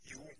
Ääntäminen
Ääntäminen UK : IPA : /jɛs/ US : IPA : /jɛs/